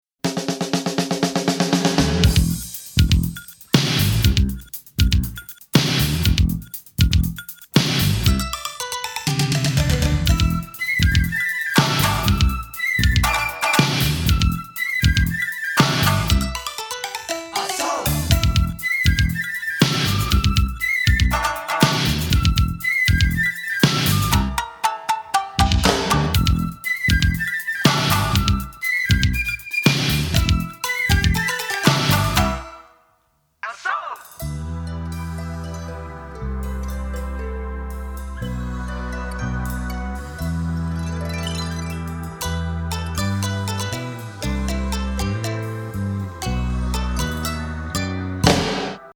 和ムード全開祭囃子インスト・グルーヴ!!